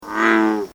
The advertisement call of the American Bullfrog can be described as a very loud low-pitched two-part drone or bellow.
Waveform and Sonogram of Advertisement Call
Sound This is a recording of the advertisement calls of an American Bullfrog recorded during the day in Imperial County.